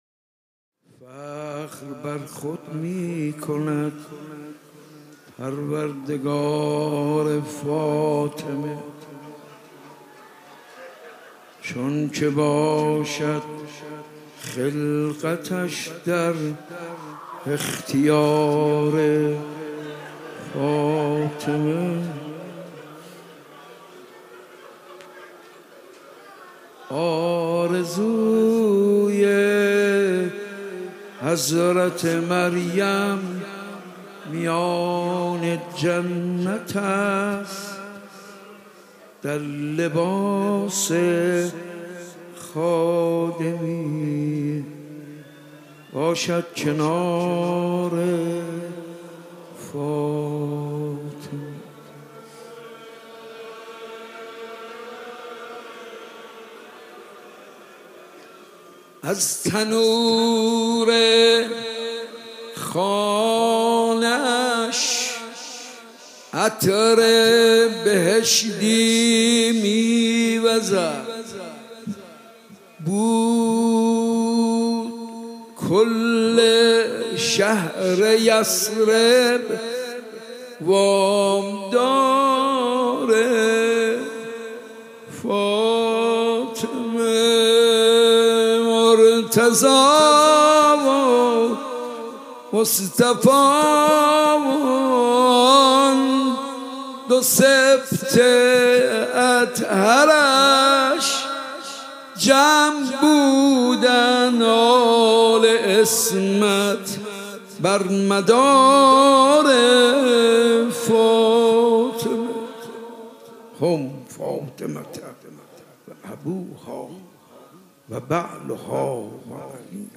منصور ارضی در مراسمی که به مناسبت دهه اول ایام فاطمیه در مسجد ارک تهران برگزار شد در رثای شهادت حضرت فاطمه زهرا (س) به نوحه و مرثیه خوانی پرداخت.